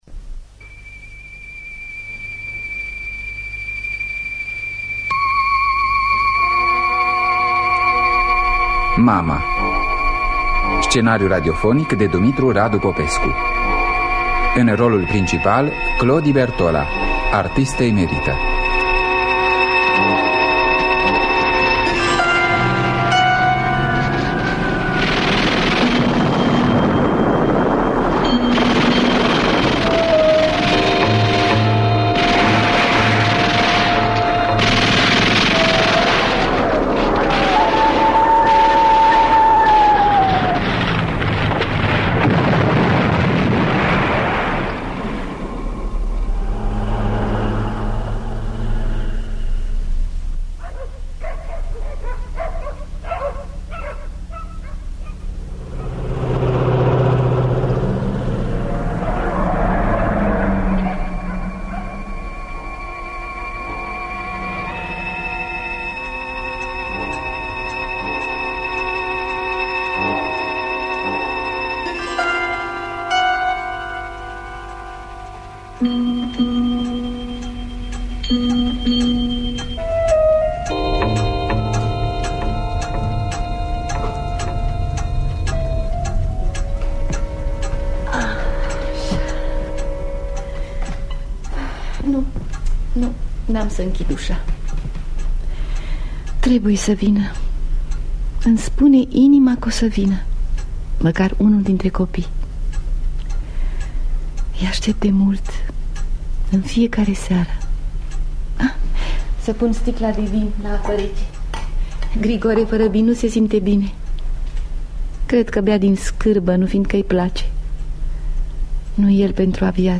Mama de Dumitru Radu Popescu – Teatru Radiofonic Online